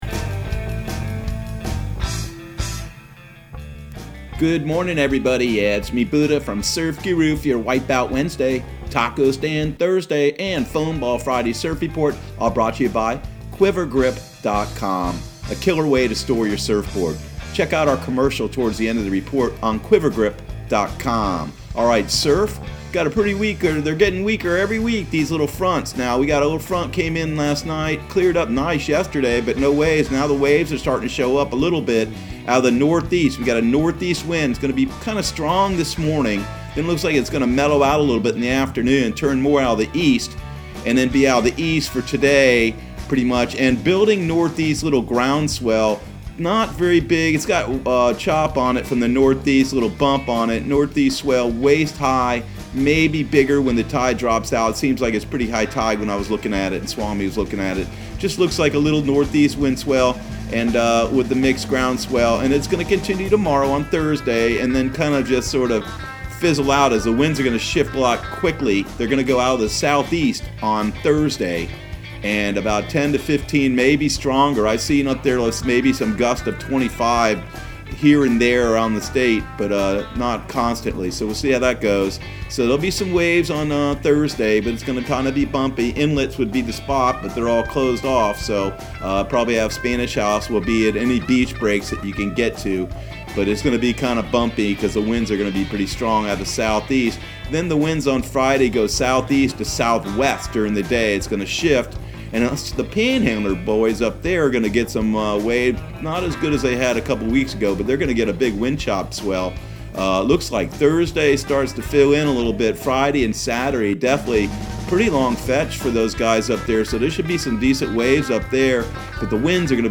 Surf Guru Surf Report and Forecast 04/22/2020 Audio surf report and surf forecast on April 22 for Central Florida and the Southeast.